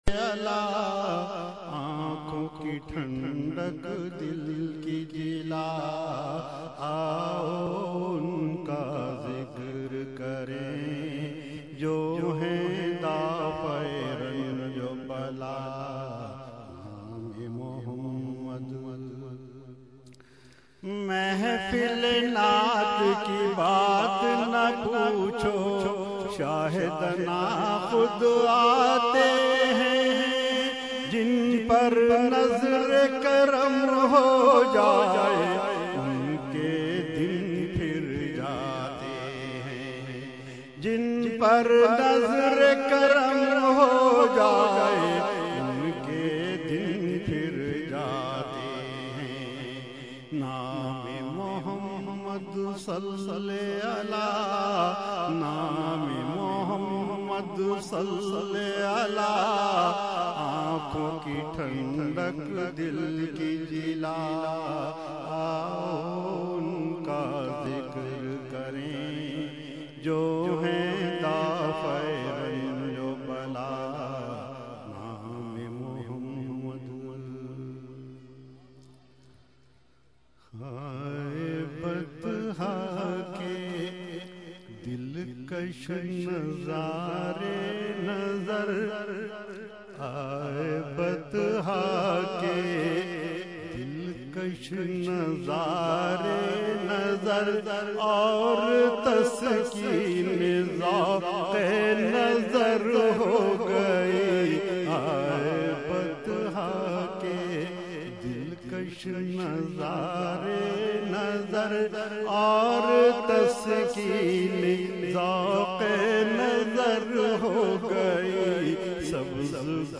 Category : Naat | Language : UrduEvent : 11veen Sharif Lali Qila Lawn 2015